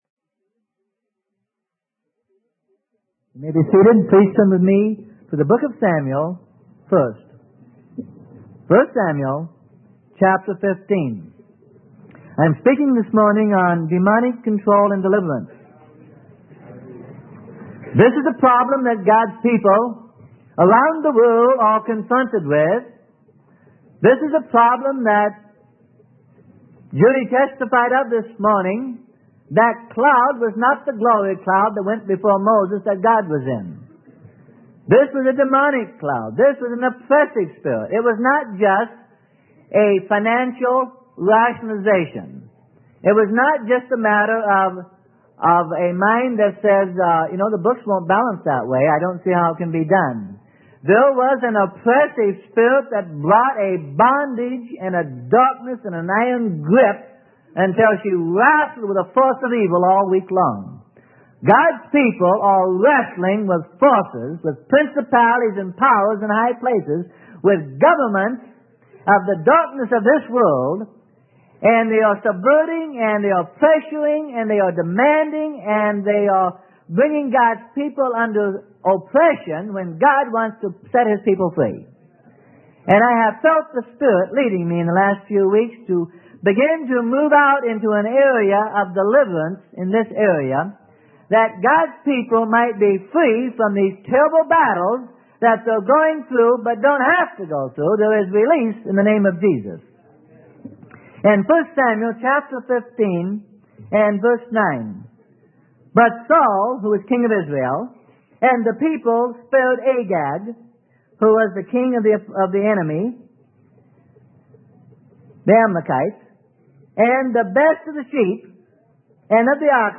Sermon: Demonic Control and Deliverance - Freely Given Online Library